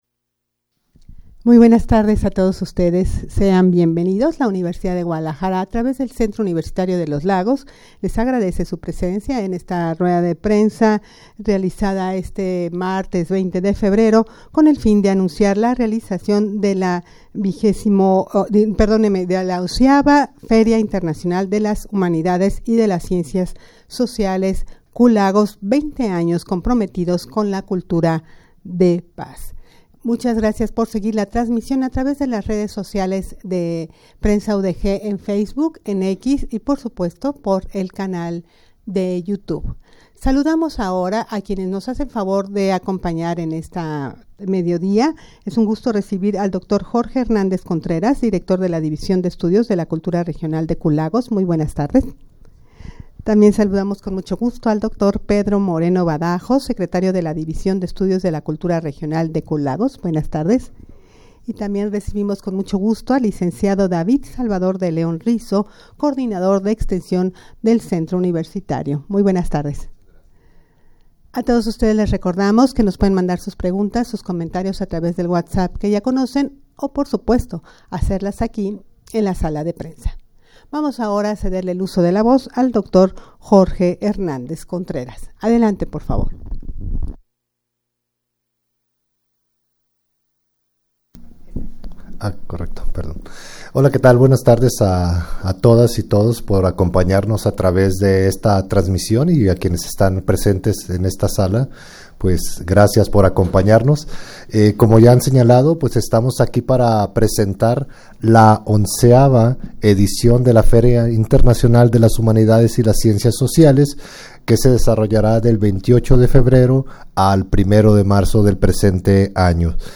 Audio de la Ruda de Prensa